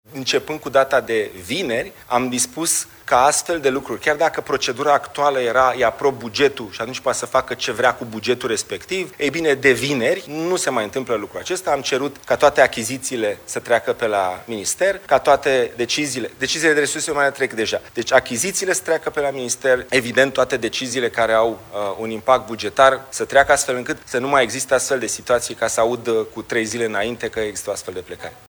Dragoș Pîslaru, ministrul Investițiilor și Proiectelor Europene: „Am cerut ca toate achizițiile să treacă pe la minister”